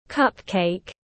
Bánh cắp-cếch tiếng anh gọi là cupcake, phiên âm tiếng anh đọc là /ˈkʌp.keɪk/
Cupcake /ˈkʌp.keɪk/